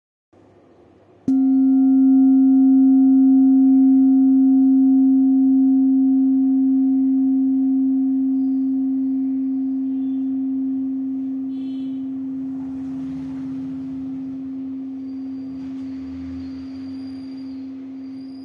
Old Hand Beaten Bronze Kopre Singing Bowl with Antique
Material Bronze
It is accessible both in high tone and low tone .